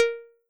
ui_select.wav